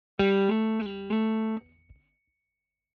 ▶Automatic Slide：スライド奏法を自動で適用
Ample_Slide2.mp3